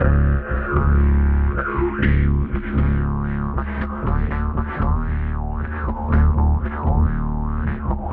DidgeBassLine_118_D.wav